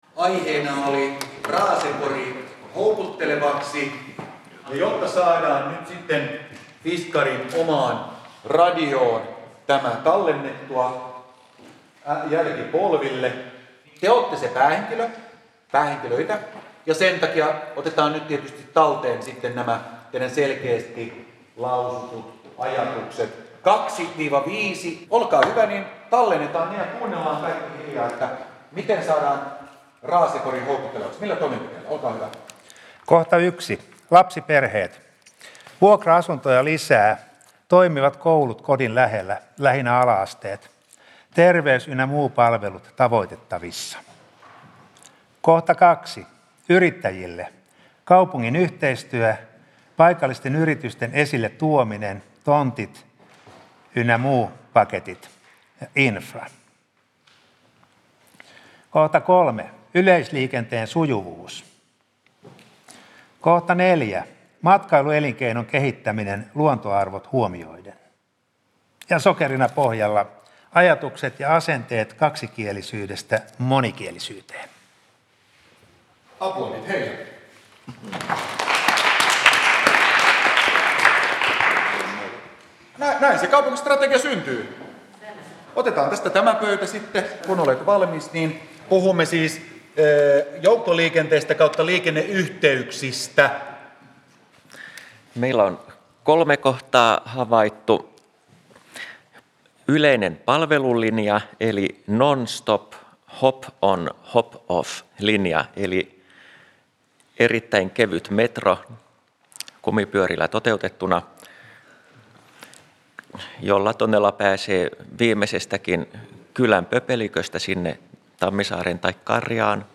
Kaupunginvaltuusto hyväksyy lopullisen strategian toukokuussa 2018. Fiskarsissa järjestettiin aiheen tiimoilta avoin workshop 13.12.2017. Radiofiskars taltioi ryhmätyönä toteutetun ideoinnin loppupäätelmät.